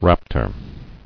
[rap·tor]